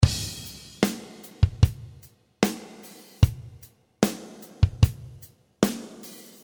Classic rock rhythm in 75 bpm.
Big bass drum and long reverb on the snare promise to give you
The right feeling in ballads rock style.